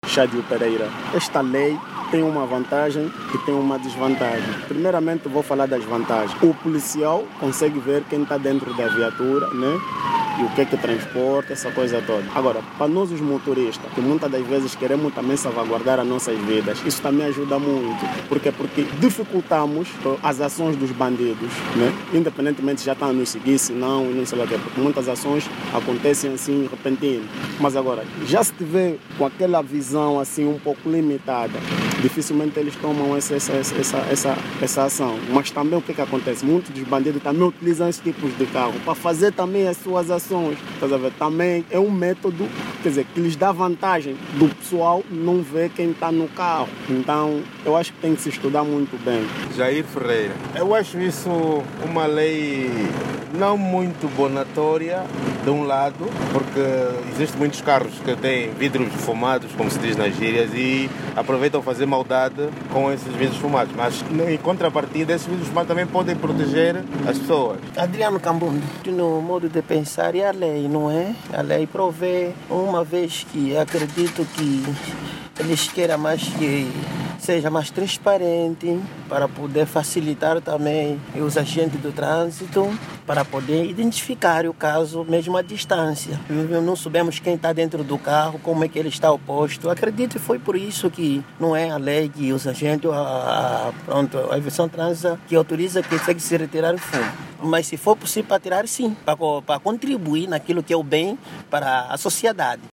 E a rádio nova ouviu em Luanda alguns automobilistas sobre esta medida de retida de películas vulgo vidros fumados nas suas viaturas.
VOX-TRANSITO-19HRS.mp3